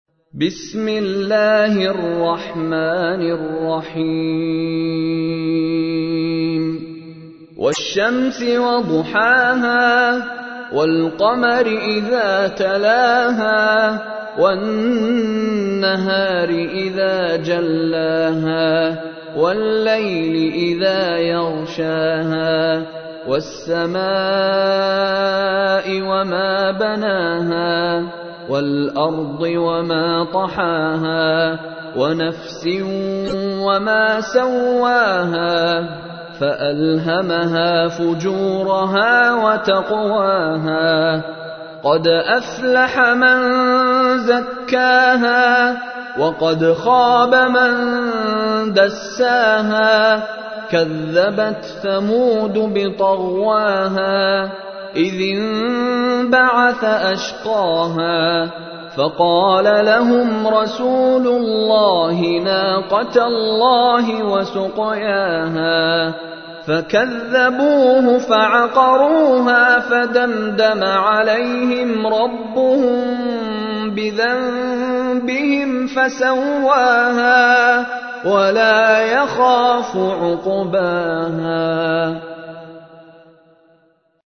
تحميل : 91. سورة الشمس / القارئ مشاري راشد العفاسي / القرآن الكريم / موقع يا حسين